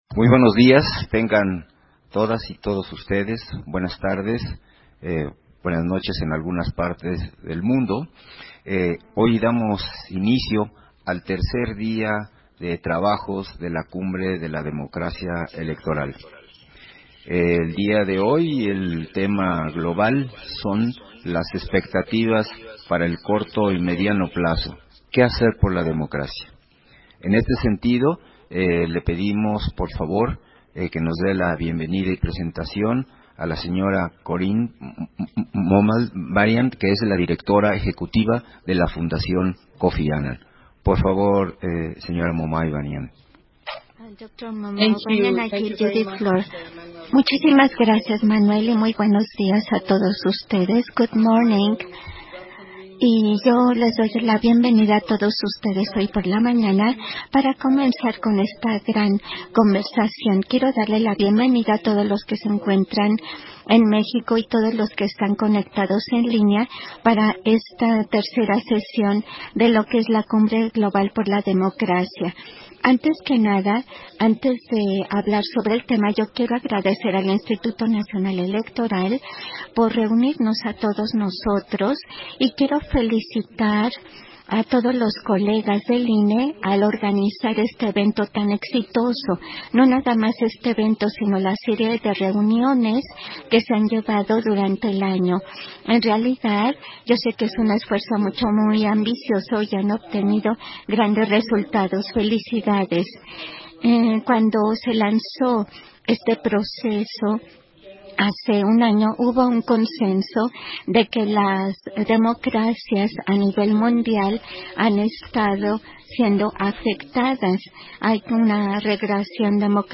220922_AUDIO_-CONFERENCIA-INAUGURAL-CUMBRE-DE-LA-DEMOCRACIA-ELECTORAL
Versión estenográfica de bienvenida y conferencia inaugural en el tercer día de la Cumbre Global de la Democracia Electoral